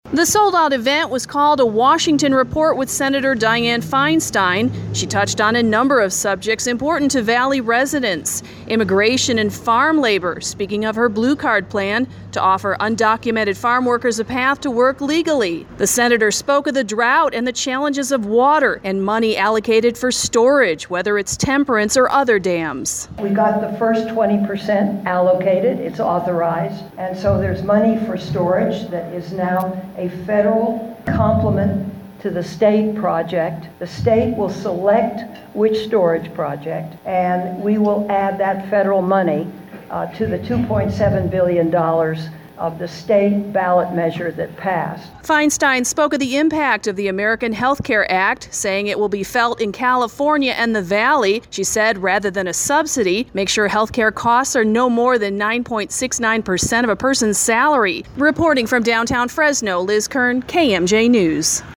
FRESNO, CA (KMJ) – U.S. Senator Dianne Feinstein visited Fresno, Thursday, speaking at a luncheon called a “Washington report with Senator Dianne Feinstein,” hosted by the Central Valley Community Foundation.